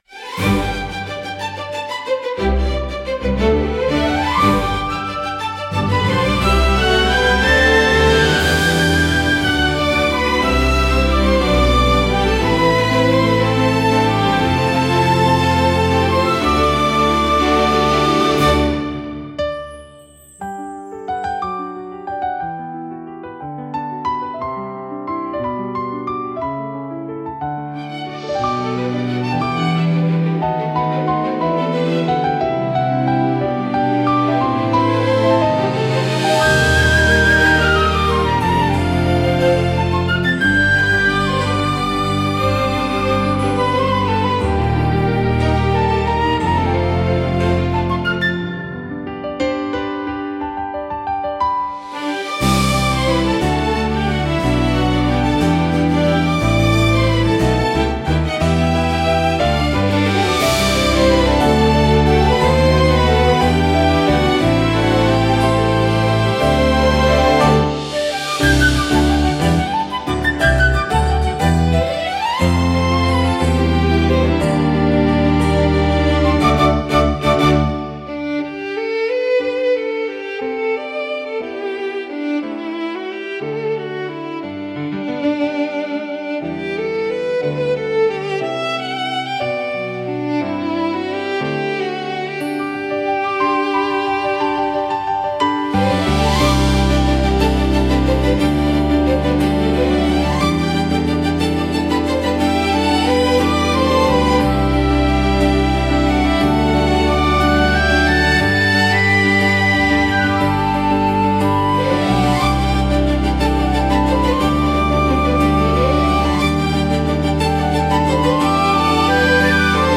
ロマンティックで華やかな場にぴったりのジャンルです。